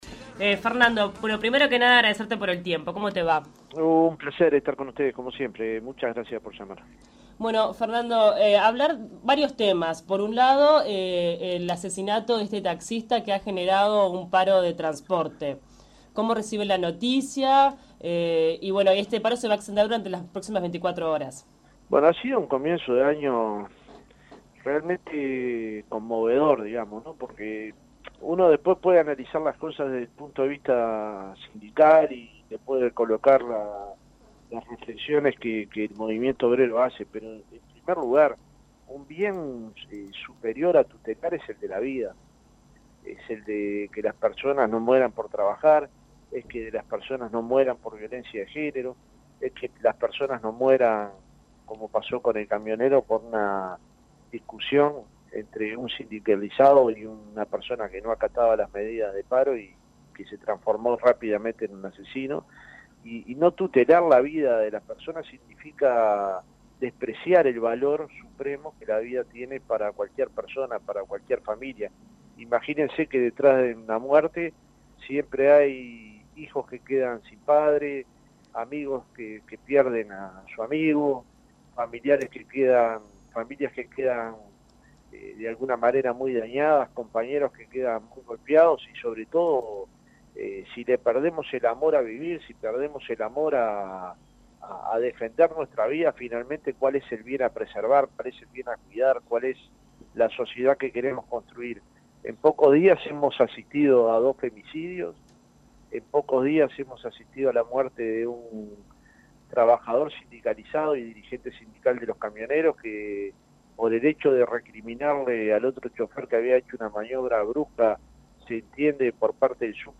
Fernando Pereira, Presidente del PIT CNT dialogó con Fuentes Confiables sobre una serie de episodios que han conmovido al país en las últimas horas.